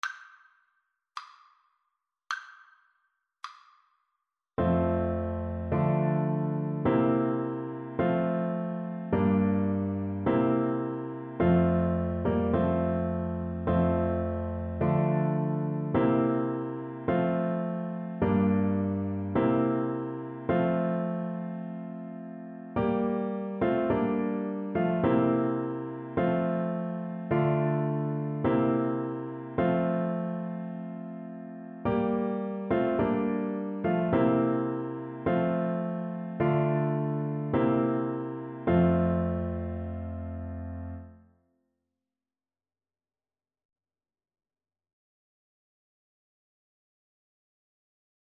Flute
2/4 (View more 2/4 Music)
F major (Sounding Pitch) (View more F major Music for Flute )
Andante
Traditional (View more Traditional Flute Music)
world (View more world Flute Music)